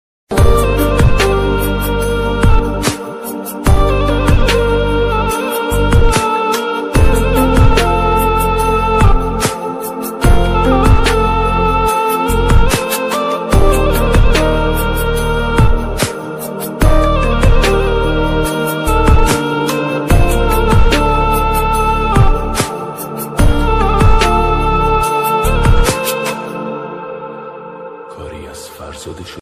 Very Sad Ringtone
Sad Remix
Background Sad Music